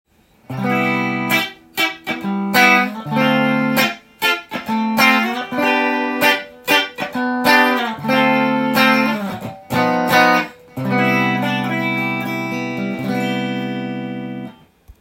まずはシングルコイルでコード弾きをしてみました。
シングルコイルタイプのほうがシャキシャキして細い音がして